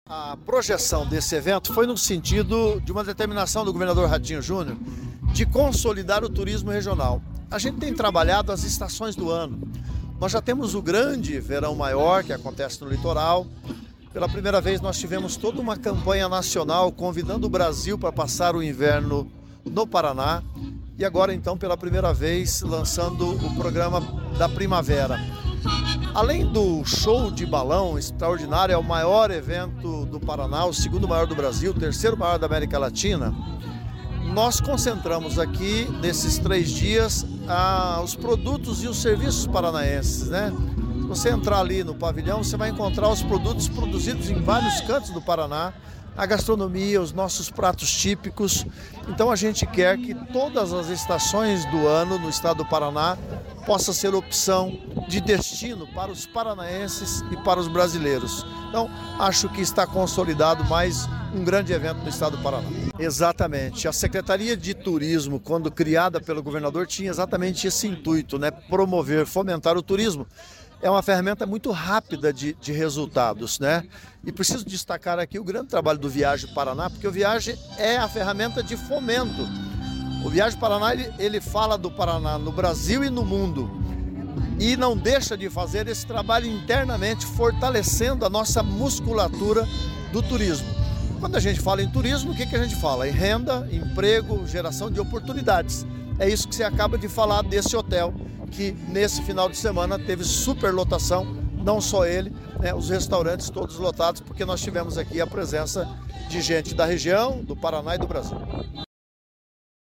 Sonora do secretário do Turismo, Leonaldo Paranhos, sobre as atrações do Festival da Primavera em Castro | Governo do Estado do Paraná